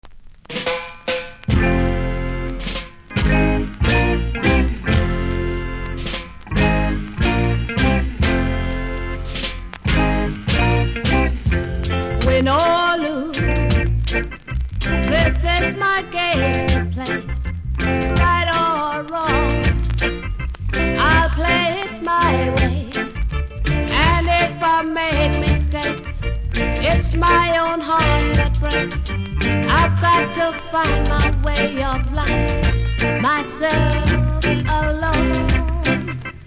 両面プレス起因のノイズありますがプレイは問題レベル。